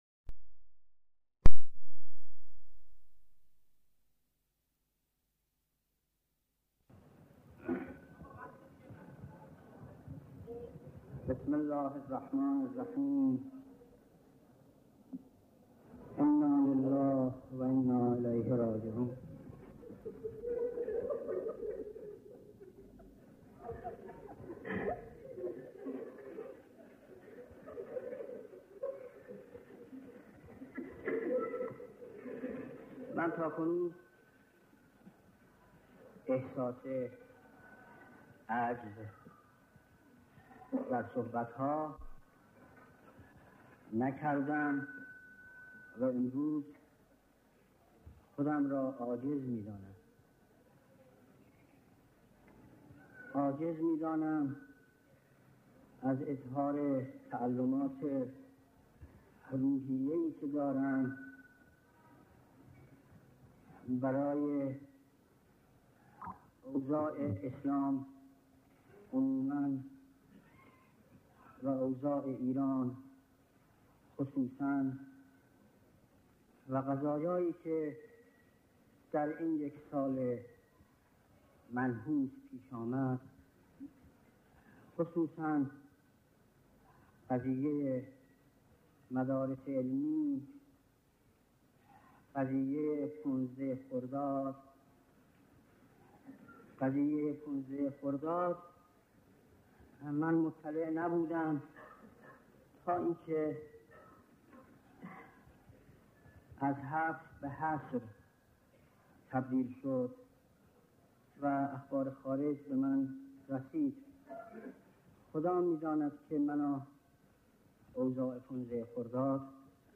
مکان : قم، مسجد اعظم